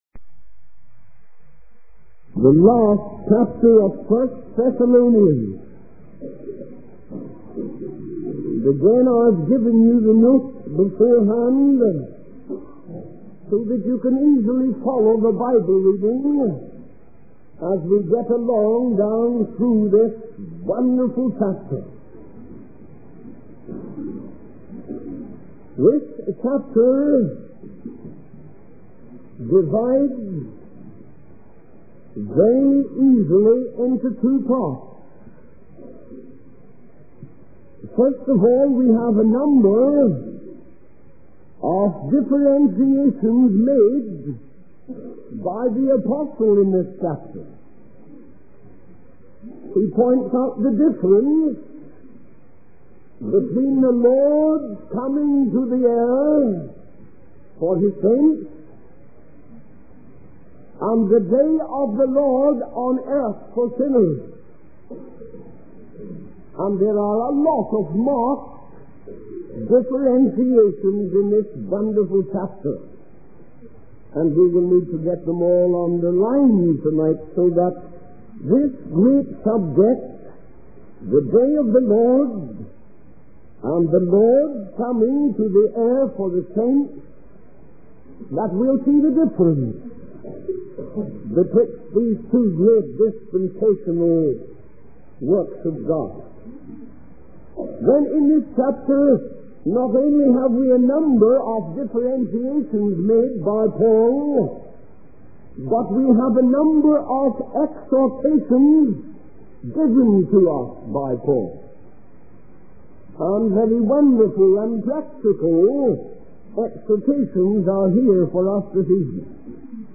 In this sermon, the preacher emphasizes the importance of rejoicing in the Lord regardless of the circumstances.